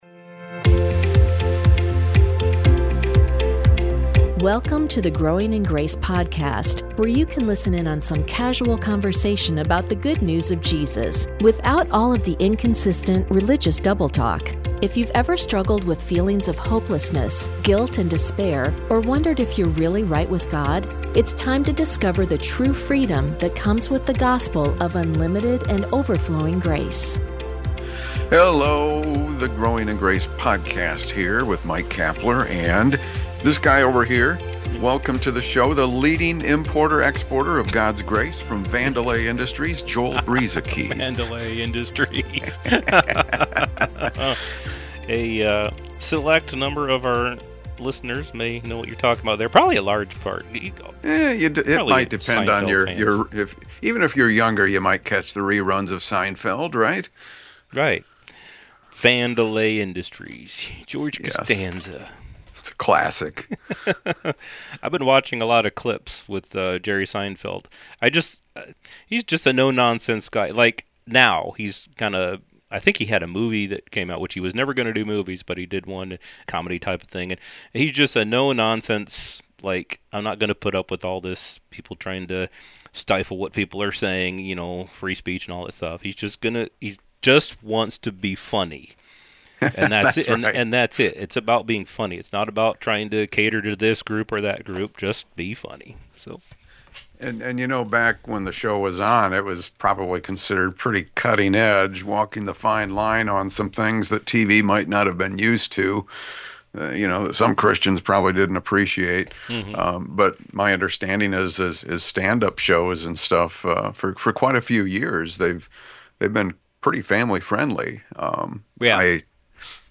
Growing in Grace is a weekly program featuring informal conversation to help with growth in understanding the gospel, and to live in the freedom that comes through Jesus Christ.